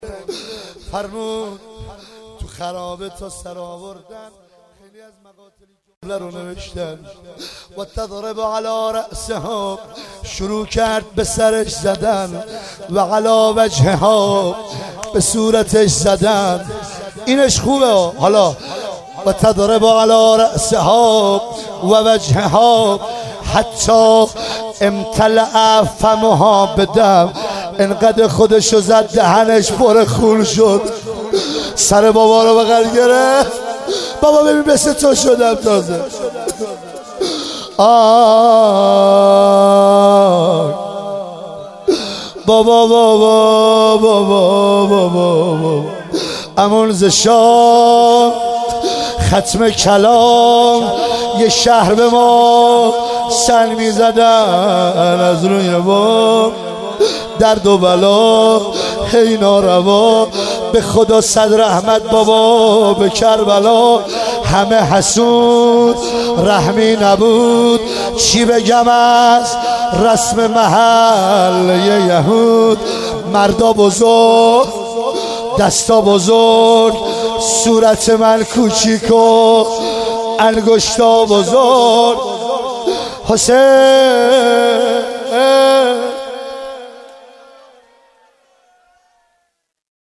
شب 13 محرم 1398 – ساری
شور من با تو آقا توی هیئت رفیق شدم